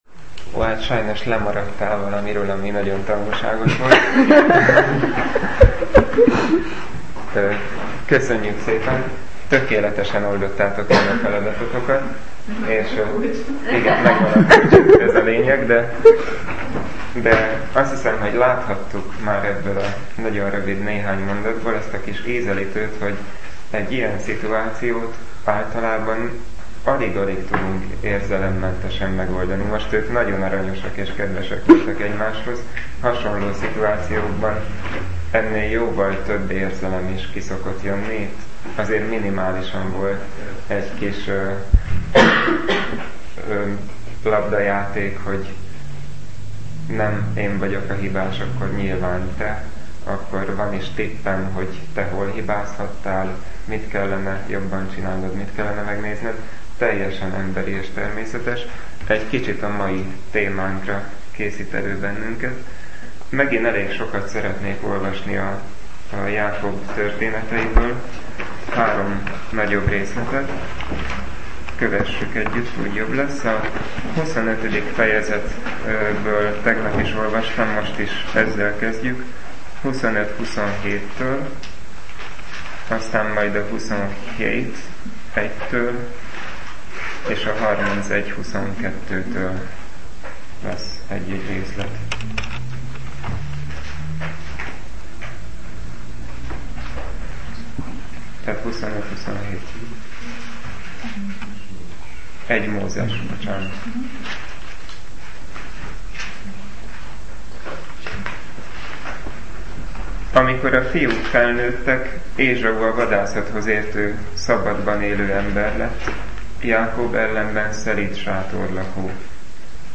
(Elhangzott a 2009-es tavaszi csendeshétvégén.)